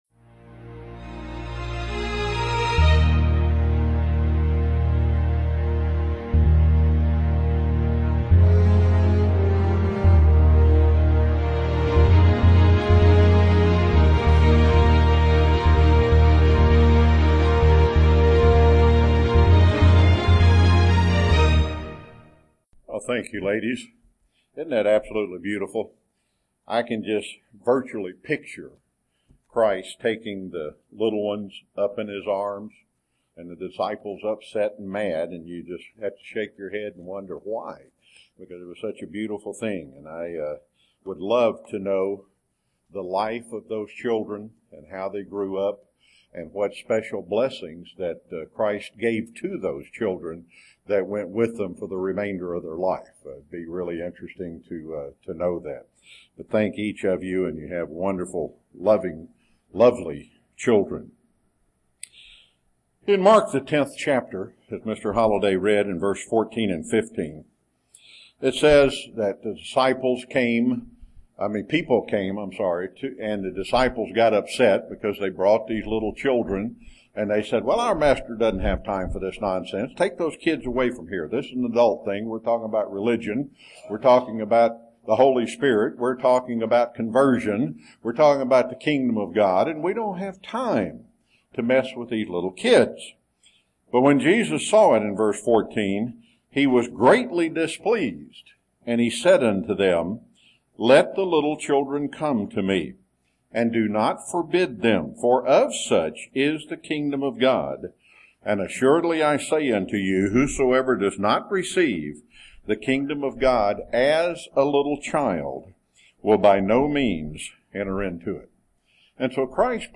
Given in Chattanooga, TN
UCG Sermon Studying the bible?